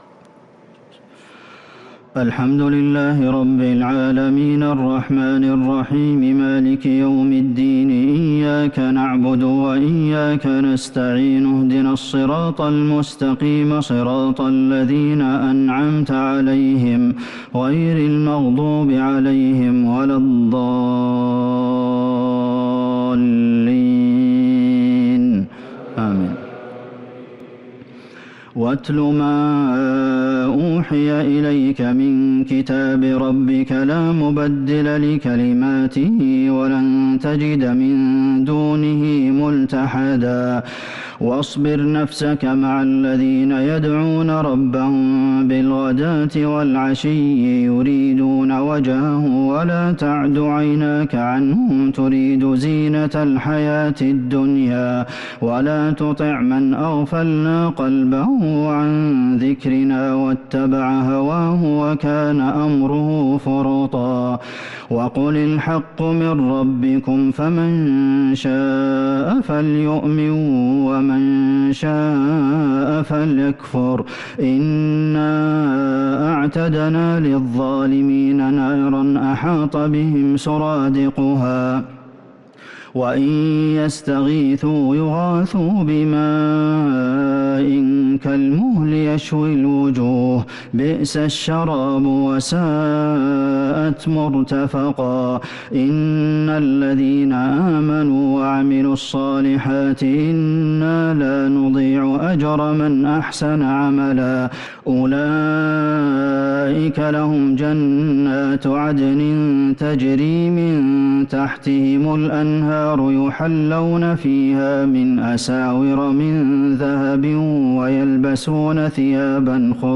صلاة التراويح ليلة 20 رمضان 1443 للقارئ عبدالمحسن القاسم - التسليمتان الأخيرتان صلاة التراويح